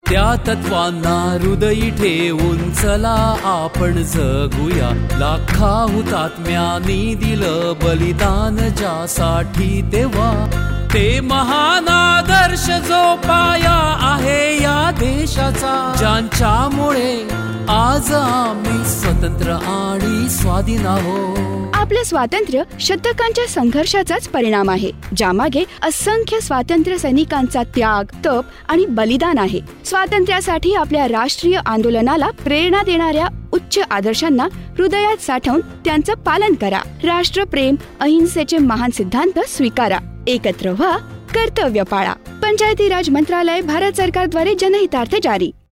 147 Fundamental Duty 2nd Fundamental Duty Follow ideals of the freedom struggle Radio Jingle Marathi